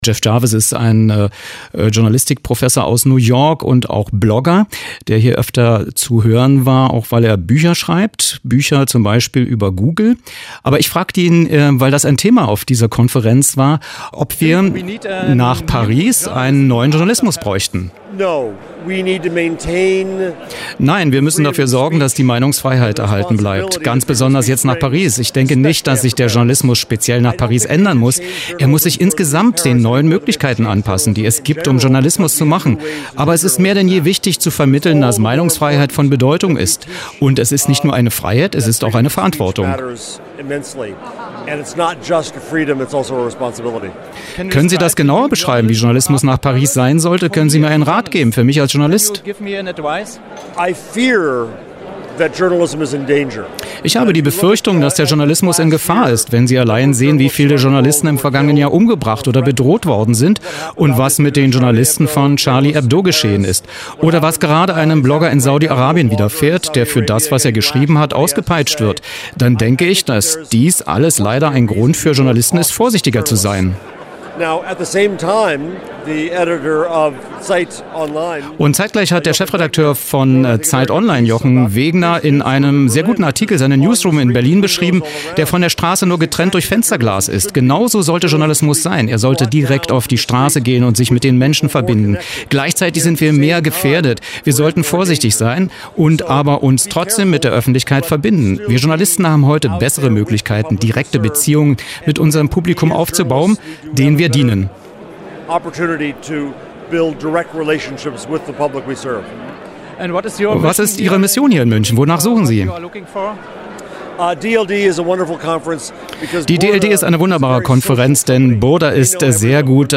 Wer: Jeff Jarvis, Journalist, Blogger, Professor
Wo: München, DLD
Was: Interview zum Journalismus nach Paris, zur DLD 2015